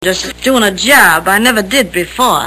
Tags: Mae West Mae West movie clips Come up and see me some time Mae West sound Movie star